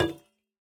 Minecraft Version Minecraft Version 1.21.5 Latest Release | Latest Snapshot 1.21.5 / assets / minecraft / sounds / block / copper_bulb / place1.ogg Compare With Compare With Latest Release | Latest Snapshot